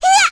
Lilia-Vox_Attack3.wav